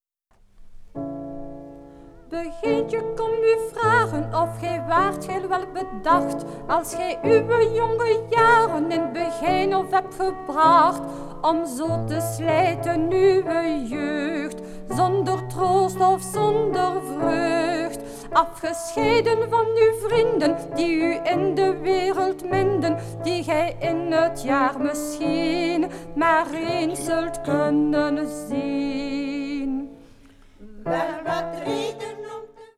begijnen van het Groot Begijnhof van Sint-Amandsberg
Klavier en solozang:
Studio G.S.T. Gent.